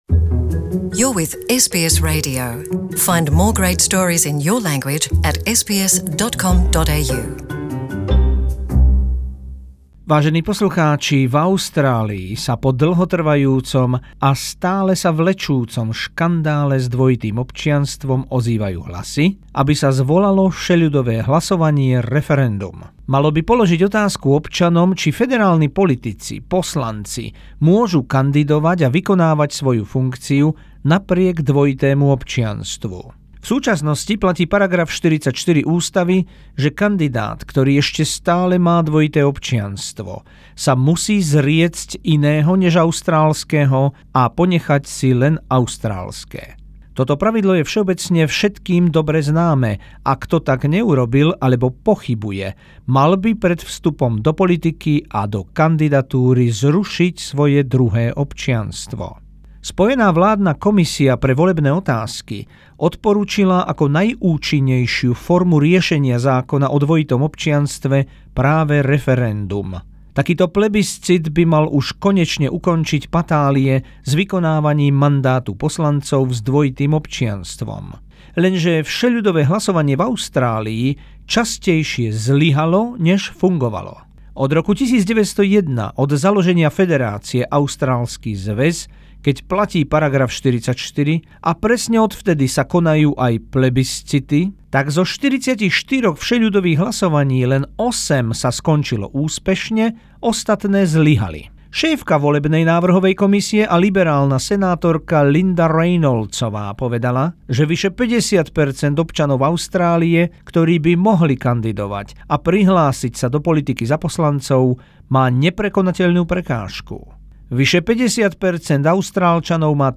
Zo spravodajskej dielne SBS o možnosti vyhlásenia všeľudového hlasovania za zmenu ústavy o dvojitom občianstve v Austrálii